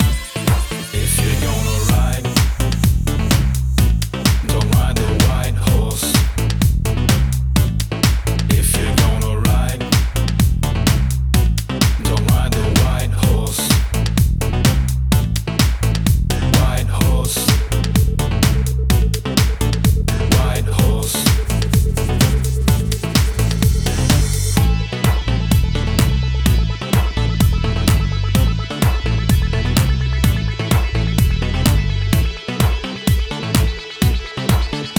Жанр: Хаус